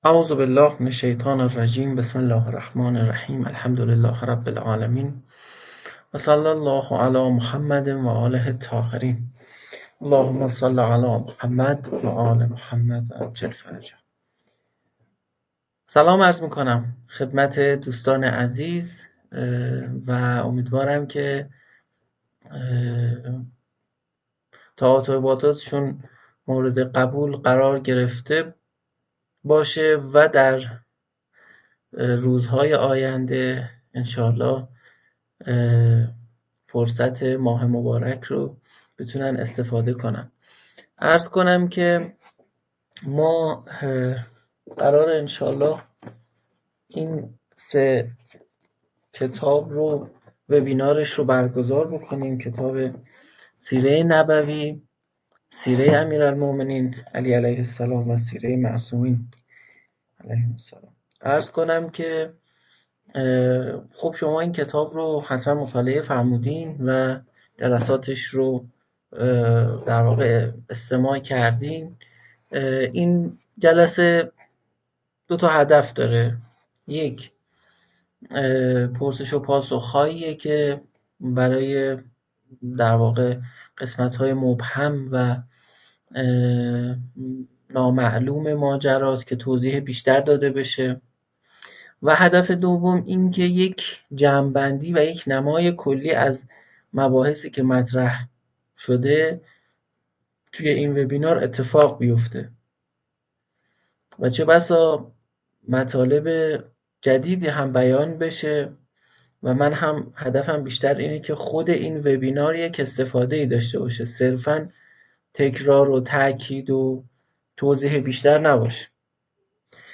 انسان شناسی (بینش مطهر) - جلسه-پرسش-و-پاسخ